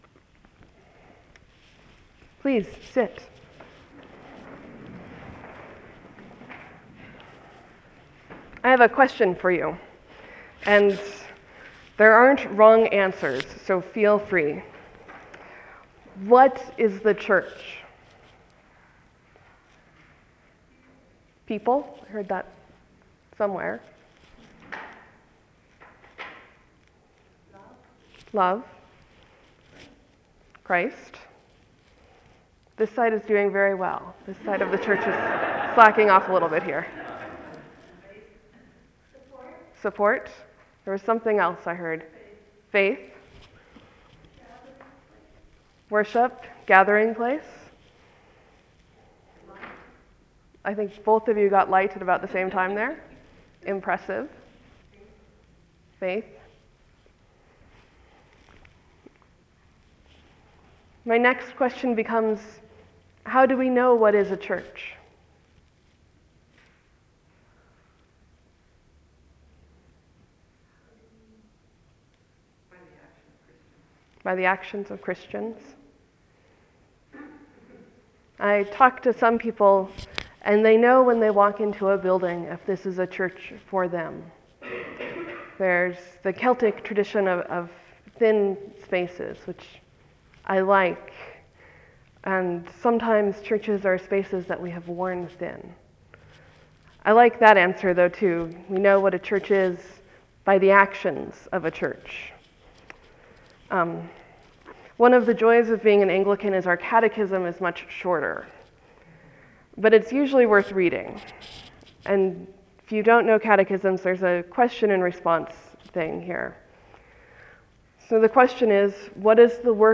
An Anglican/Episcopal priest, bibliophile, dog owner, and Montanan